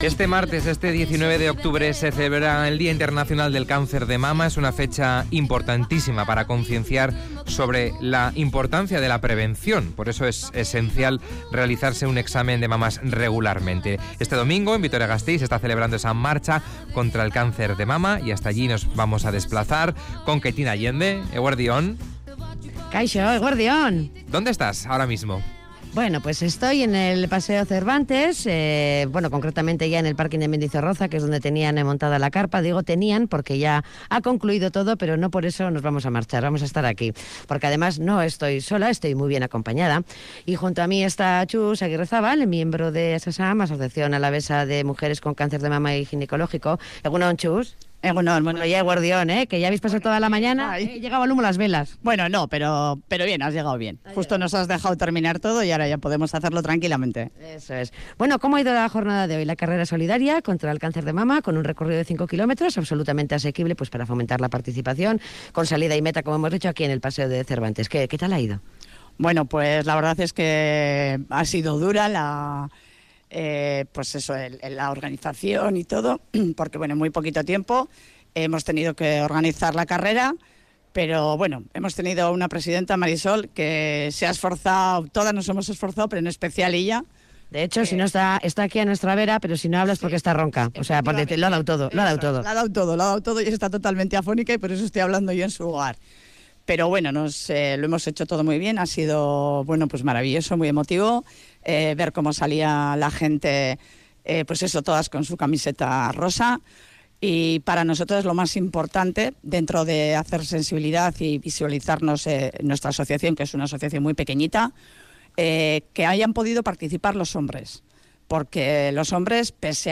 Audio: La unidad móvil se ha desplazado al paseo de Cervantes donde se ha llevado a cabo la marcha solitadia organizada por ASAMMA, Asociación Alavesa de Mujeres con cáncer de mama y ginecológico.